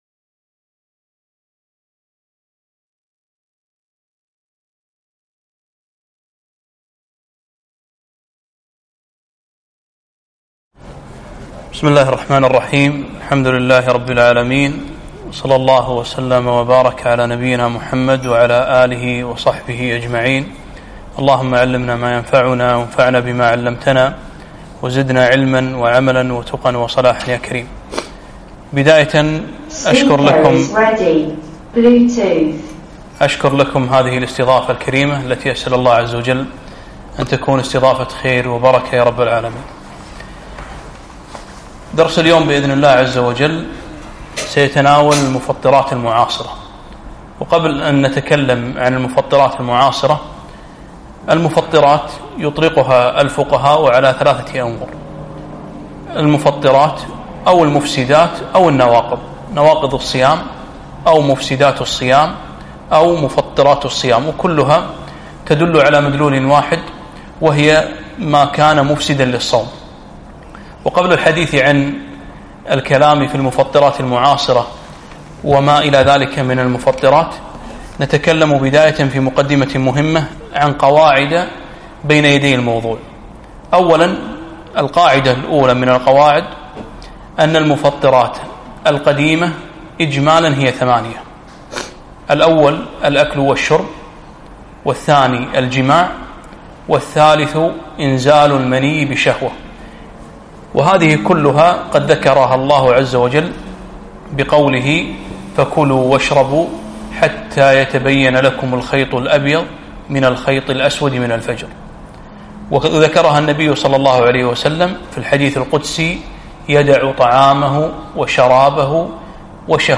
كلمة - المفطرات المعاصرة